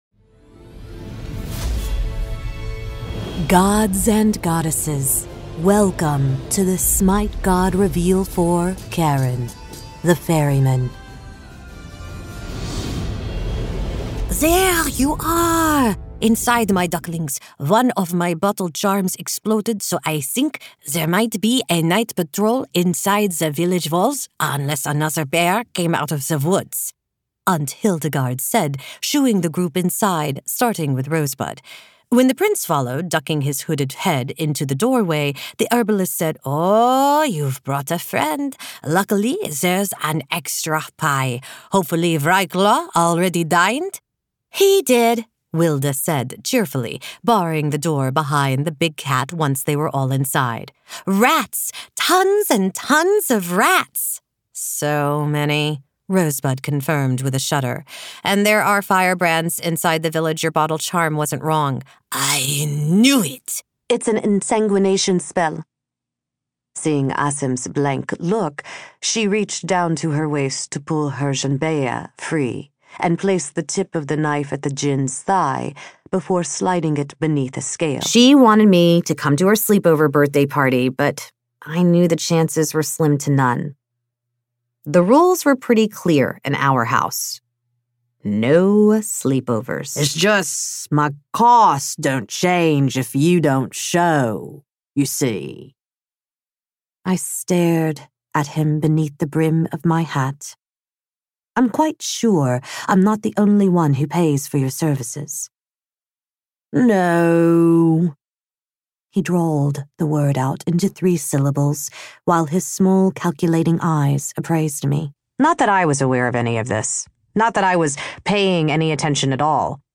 Demos
Various -- including videogame, but mostly audiobooks